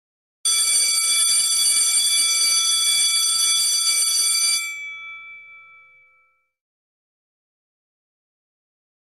Sound Buttons: Sound Buttons View : Timbre De Colegio
TIMBRE.mp3